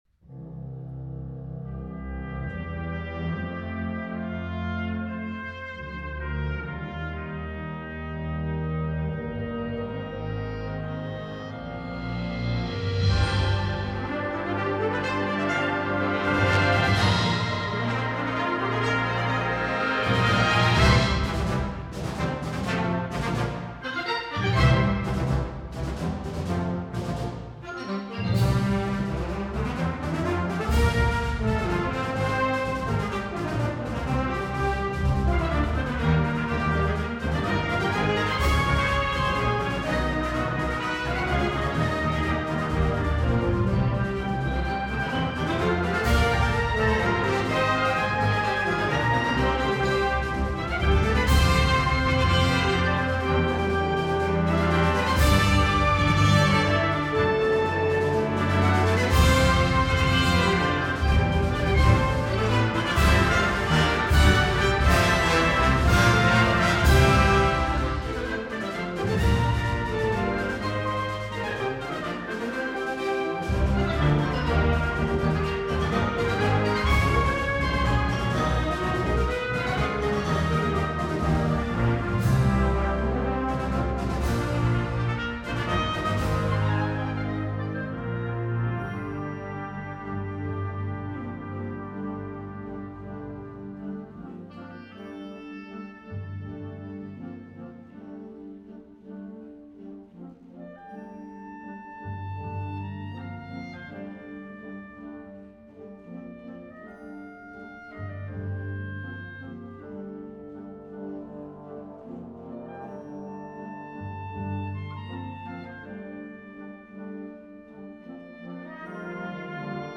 Ensemble: Concert Band